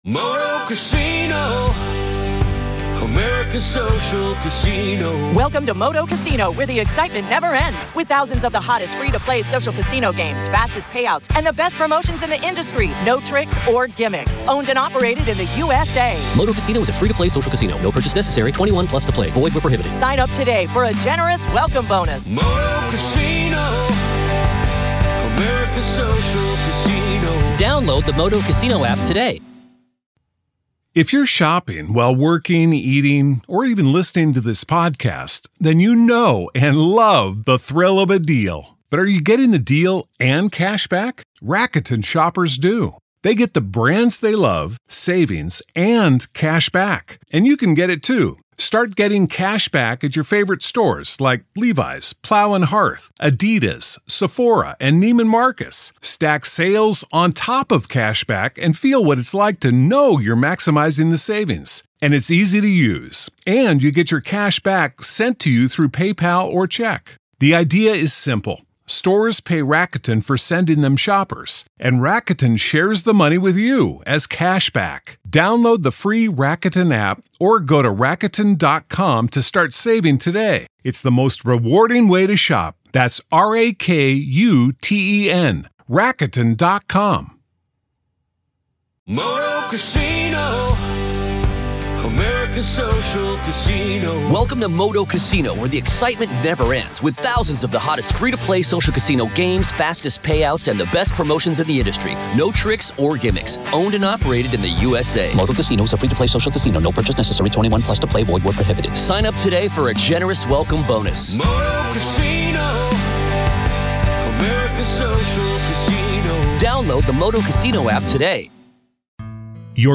True Crime Today | Daily True Crime News & Interviews / Will Possible BTK Victim's Families See Justice for Murder?